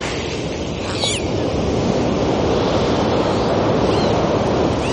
delfiny3
delfiny3.mp3